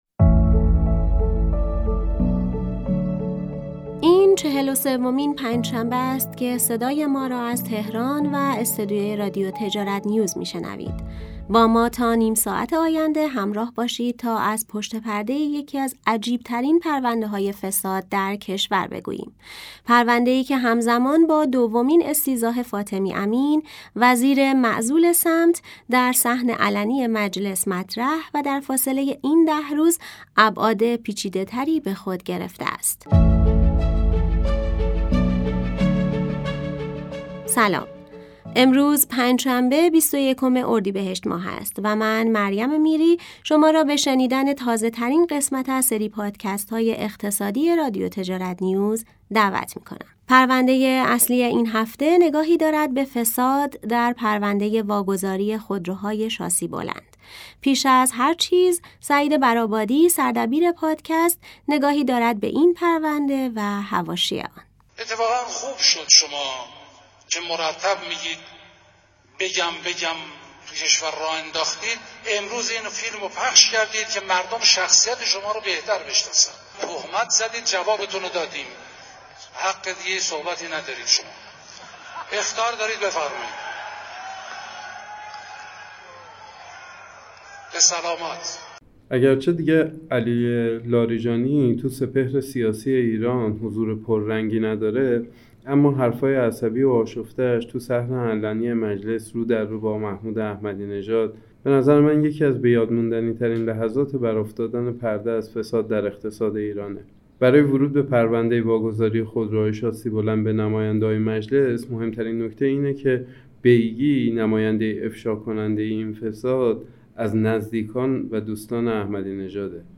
در بخش پایانی پادکست هم همچون هفته‌های قبل، قیمت بازارهای مهم ارز، سکه و طلا، خودرو و بورس را در هفته پایانی اردیبهشت ماه بررسی کرده‌ایم و از کارشناسان هر حوزه پرسیده‌ایم، تورم بر بازارها چه تاثیری داشته است.